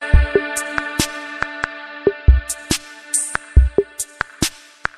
خطبة - حقوق الله على العباد